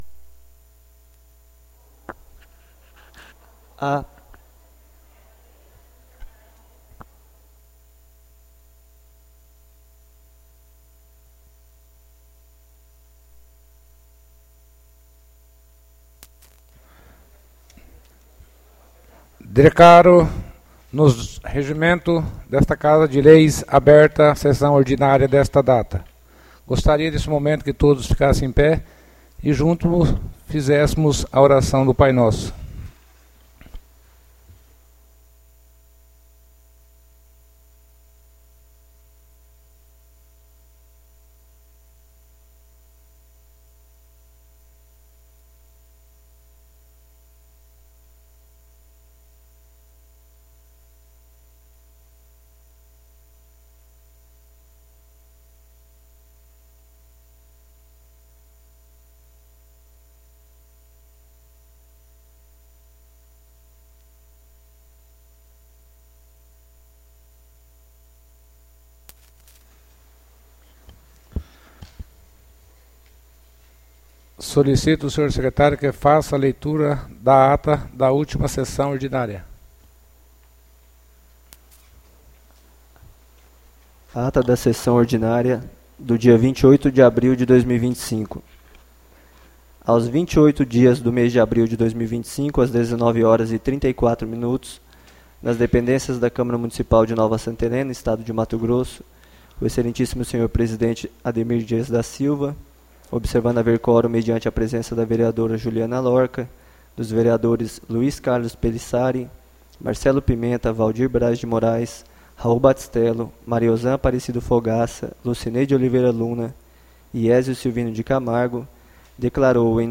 ÁUDIO SESSÃO 05-05-25 — CÂMARA MUNICIPAL DE NOVA SANTA HELENA - MT